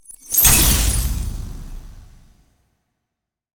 ZombieSkill_SFX
sfx_skill 18_1.wav